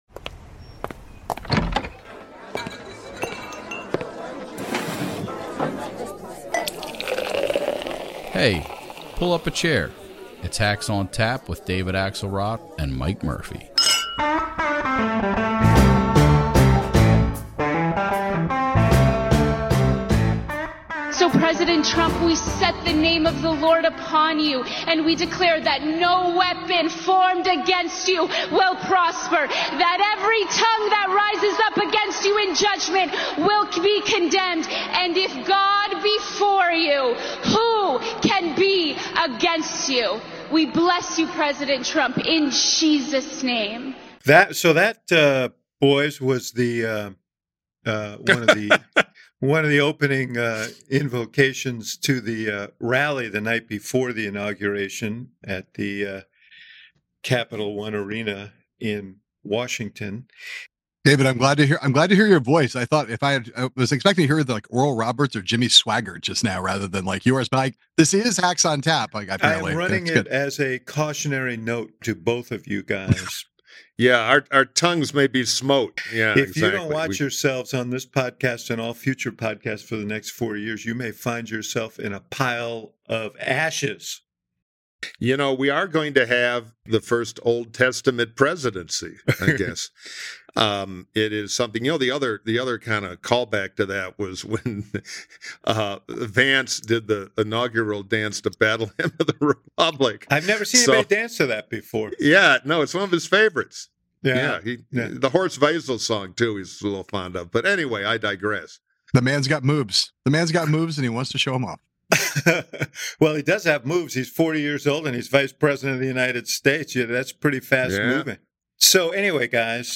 Three bleary eyed, unpardonable Hacks convened this morning to walk you through yesterday’s circus. They discuss Trump overconsumption, Biden’s pardons, Trumps pardons, setting expectations, the atomic clock of grifting, changing the constitution, and so much more.